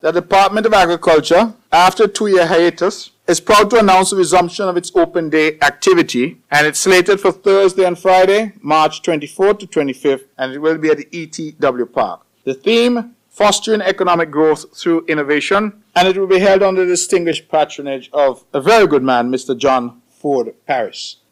That’s Premier of Nevis, the Hon Mark Brantley.